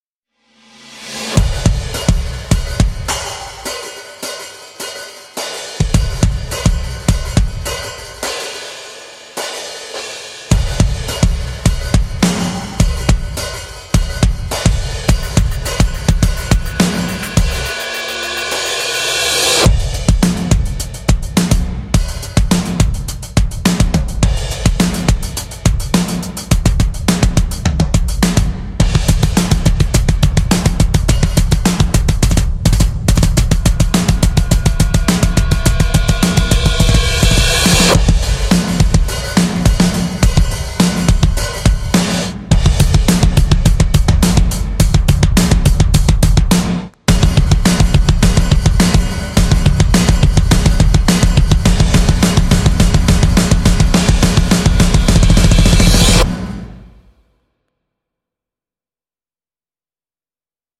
你可以使用 Heavyocity 的标志性信号链来增强、扭曲或破坏鼓声，创造出独特的效果。